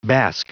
Prononciation du mot bask en anglais (fichier audio)